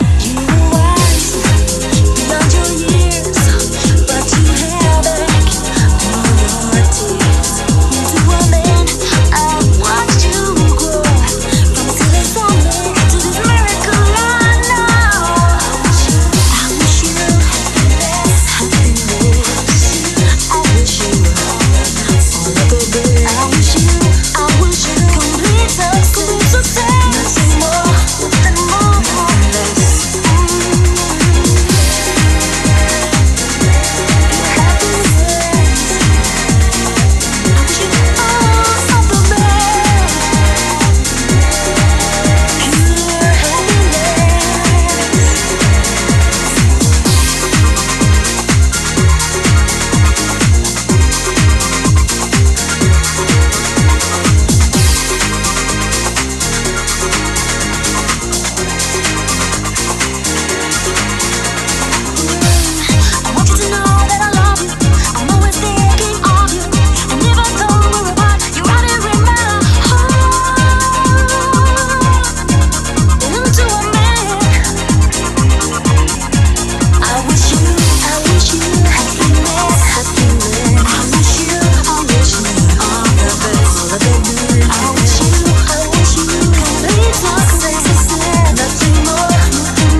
ジャンル(スタイル) DEEP HOUSE / HOUSE / TECHNO